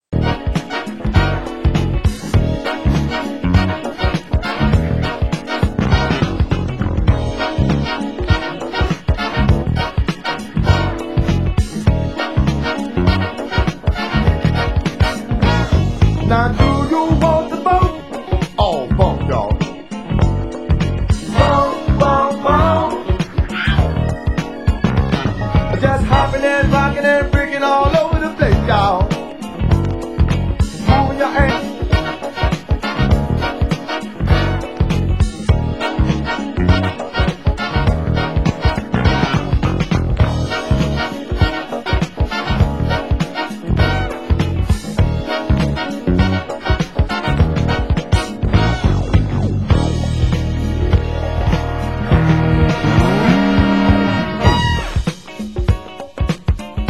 Genre: Hip Hop
vocal
instr.